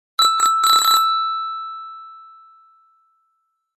zvonok
zvonok.mp3